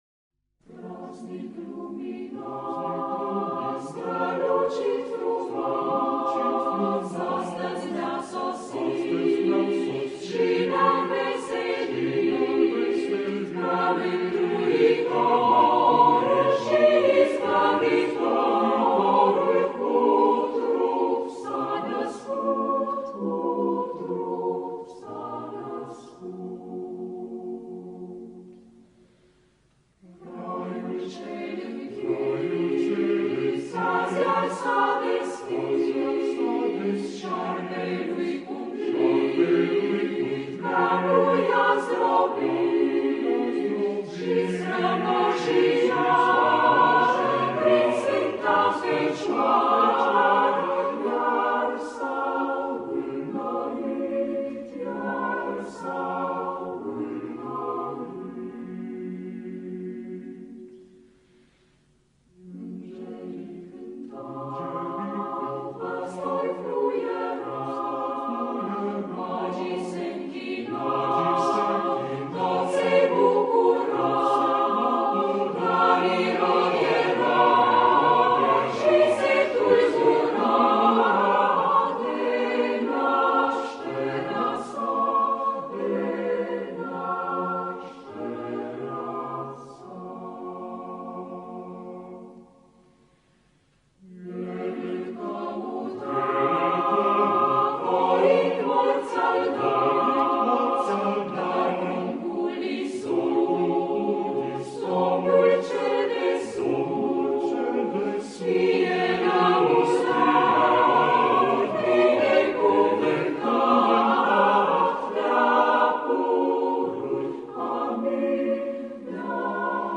Colinda-Praznic-Luminos-Corul-Madrigal-dirijor-Marin-Constantin.mp3